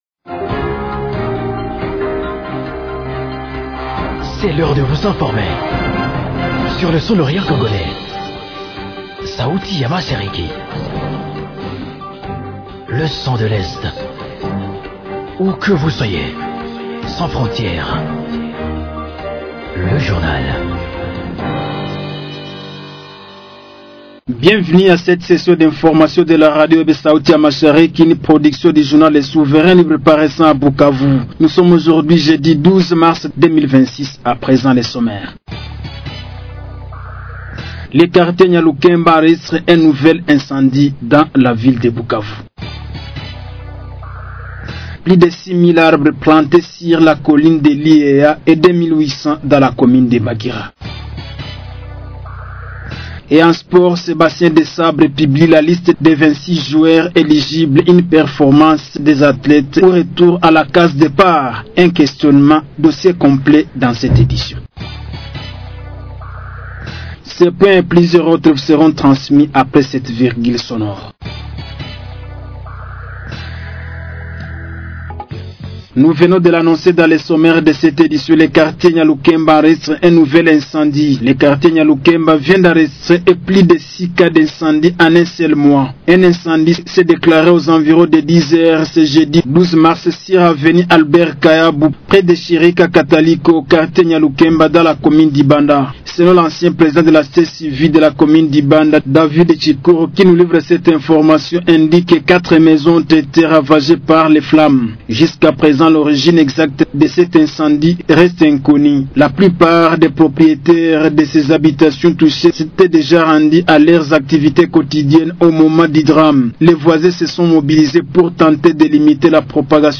Journal du 12.03.2026